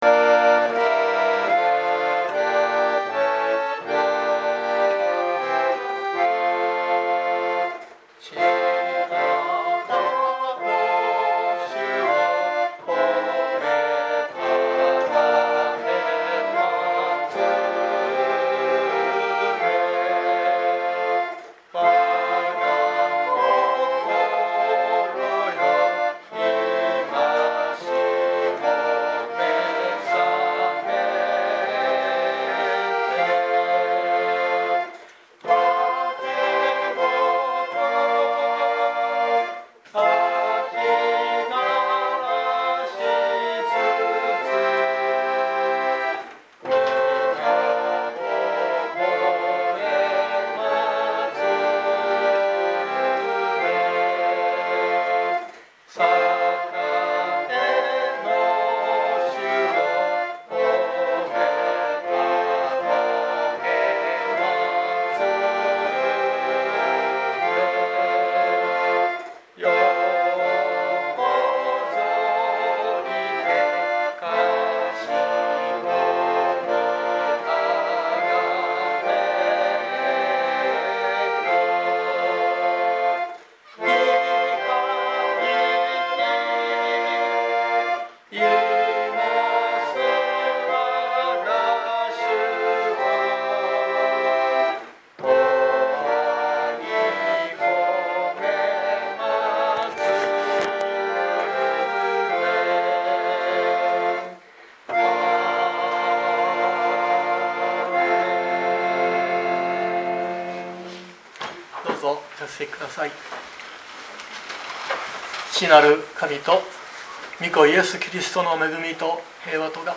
2023年02月19日朝の礼拝「メシアのしるし」熊本教会
説教アーカイブ。